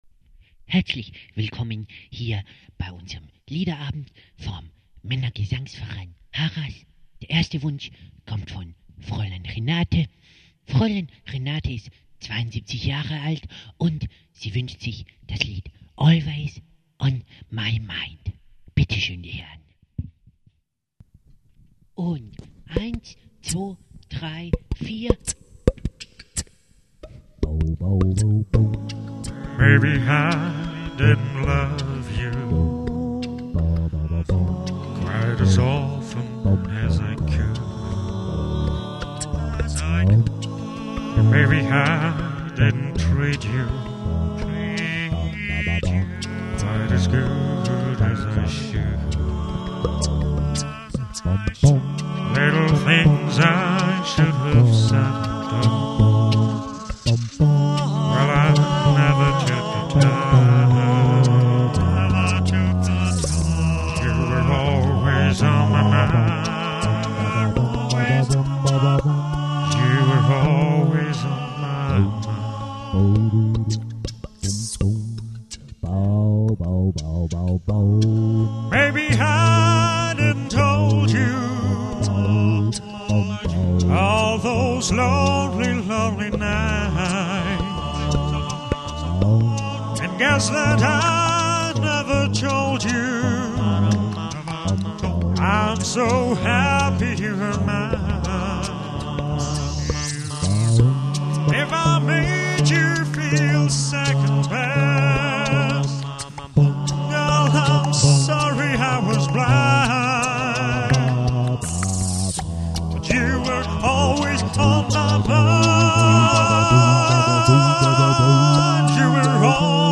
Also beschloss ich, alleine zu singen und zwar mehrstimmig.